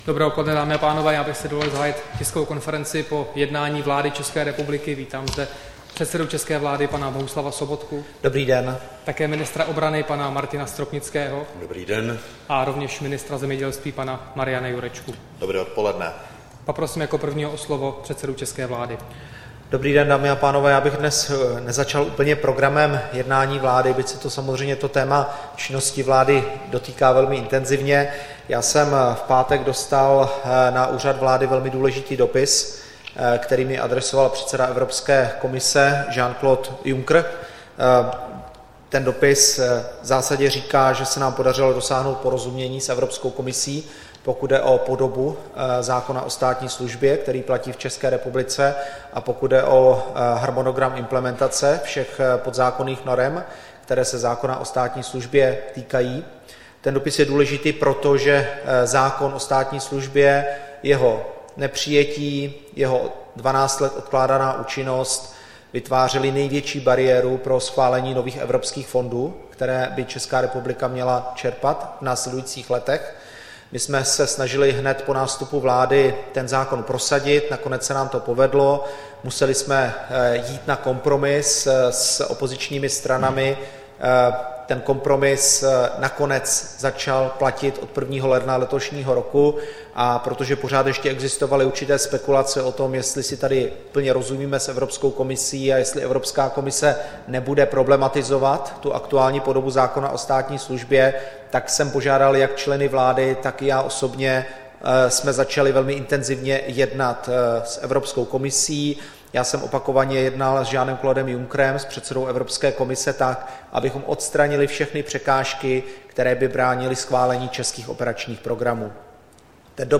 Tisková konference po jednání vlády, 16. března 2015